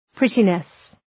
Προφορά
{‘prıtınıs}